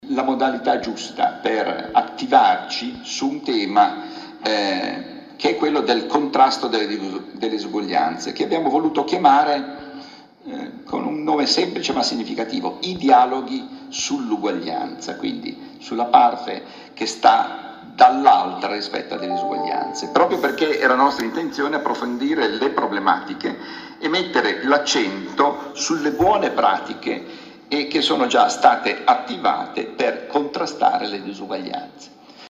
Si conclude oggi a Cagliari il congresso delle Acri – Associazione delle fondazioni e delle casse di risparmio che ha messo al centro il tema delle disuguaglianze. Ascoltiamo il presidente Francesco Profumo.